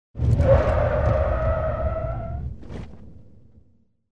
Звуки тормозов машины
На этой странице собраны звуки тормозов машин в разных ситуациях: от резкого экстренного торможения до плавного замедления.